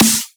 Still Gettin' It_Snare.wav